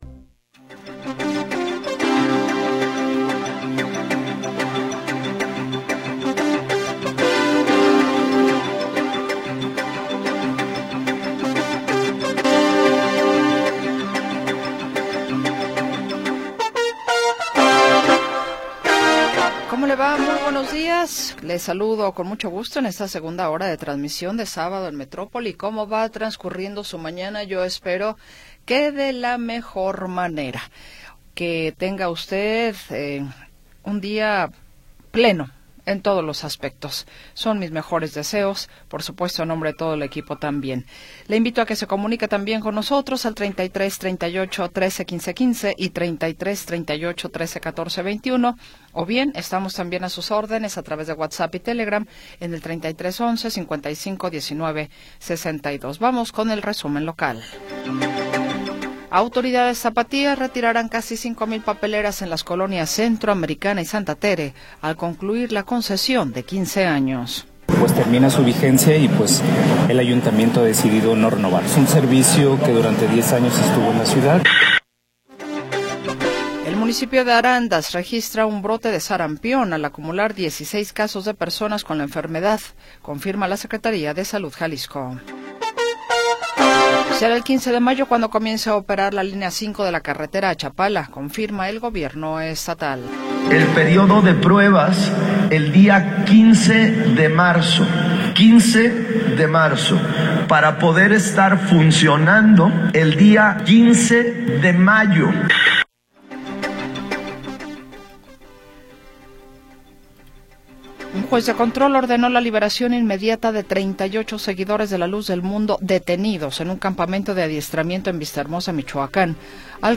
Noticias y entrevistas sobre sucesos del momento